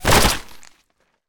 CosmicRageSounds / ogg / general / combat / weapons / mgun / flesh3.ogg
flesh3.ogg